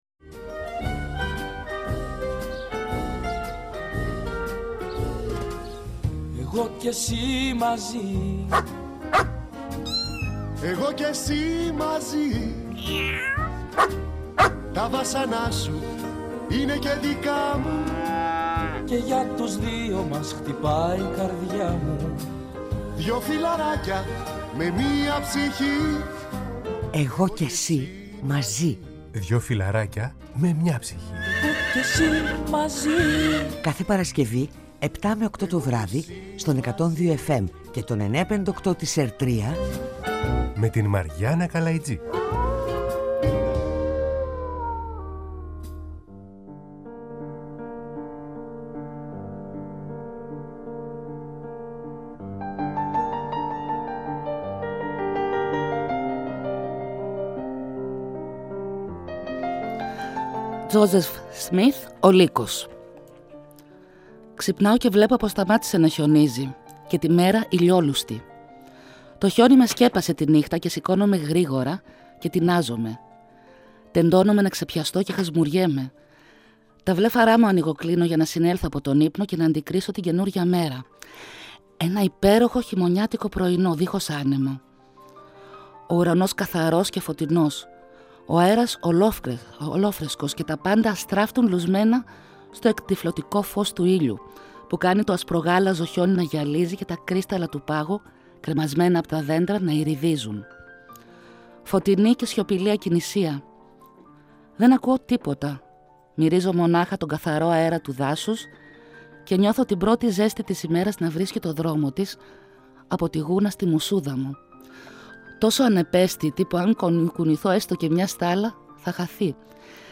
Μια κουβέντα για σκυλάκια